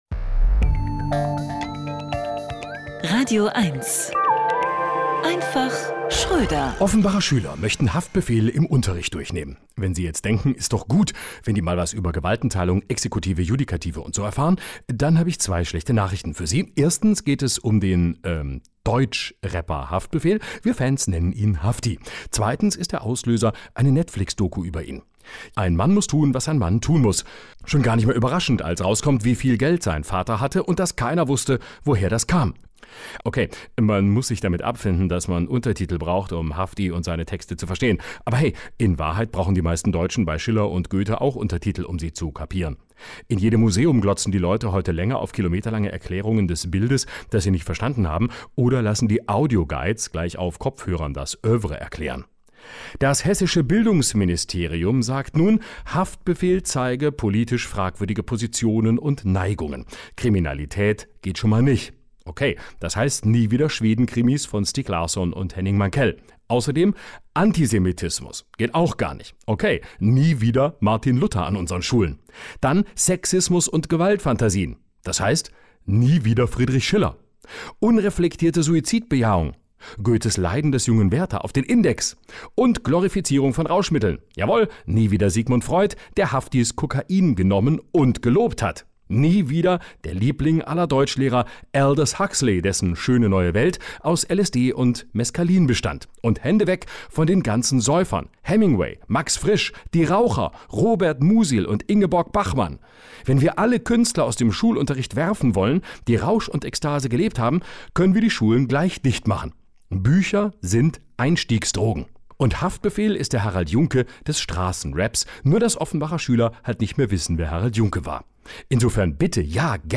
radioeins (Rundfunk Berlin-Brandenburg Saubere Komödien Unterhaltung Komödie Radioeins (rbb Komiker Chat